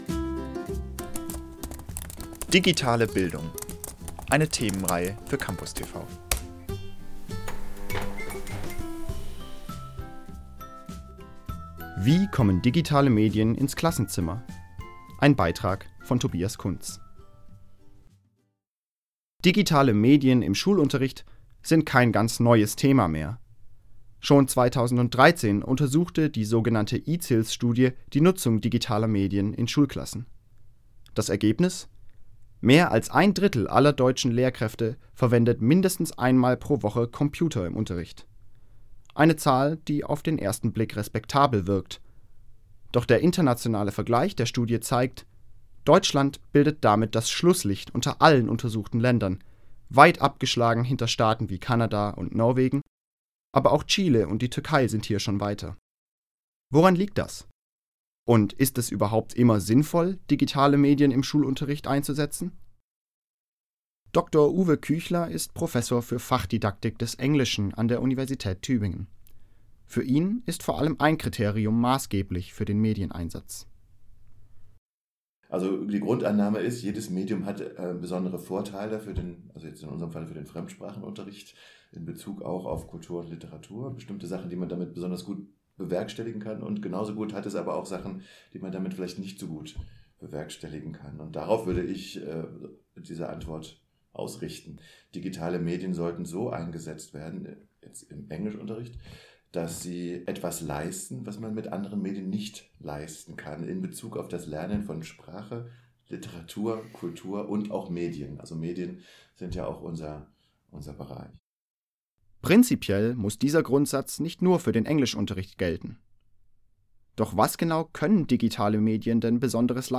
Darüber haben wir mit Wissenschaftlerinnen und Wissenschaftlern der Uni Tübingen gesprochen.